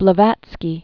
(blə-vătskē, -vät-), Helena Petrovna Hahn 1831-1891.